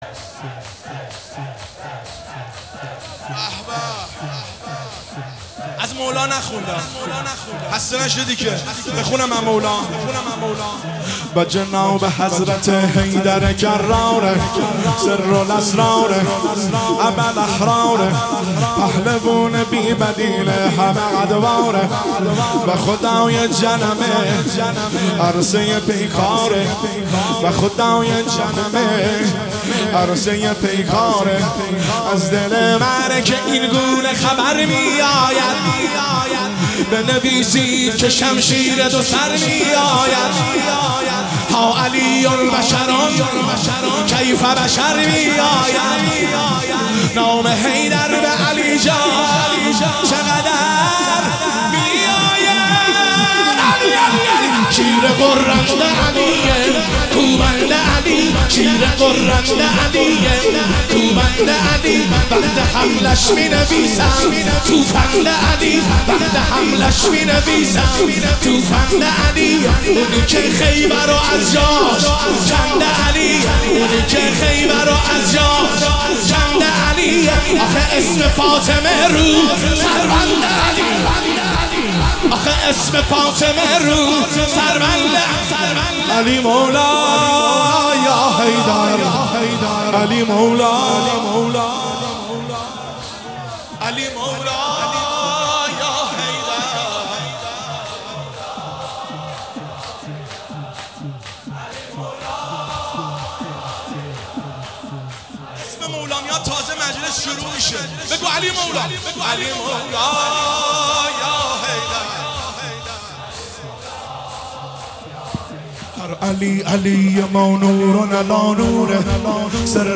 سرود عید سعید غدیر خُم 1403
شور - شب 30 صفرالمظفر 1403